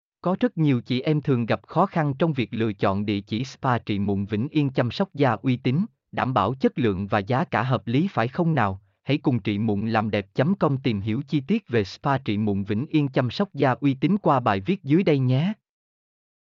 mp3-output-ttsfreedotcom-13.mp3